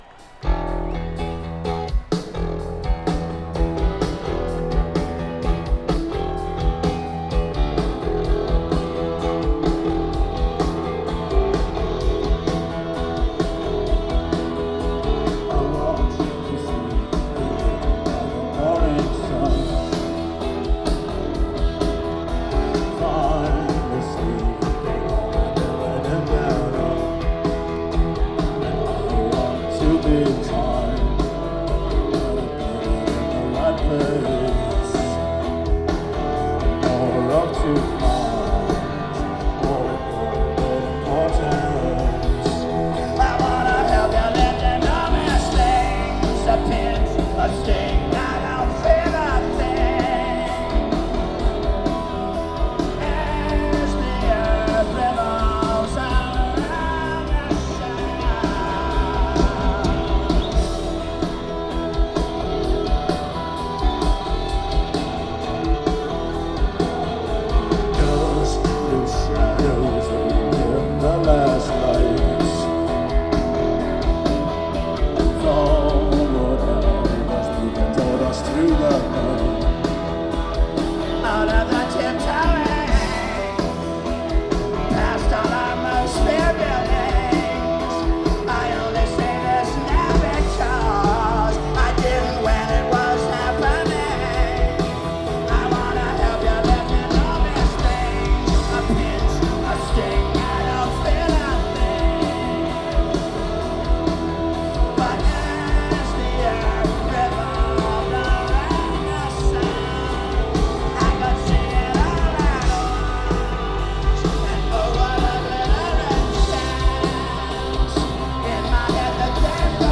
(11th Live Performance)